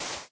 sand3.ogg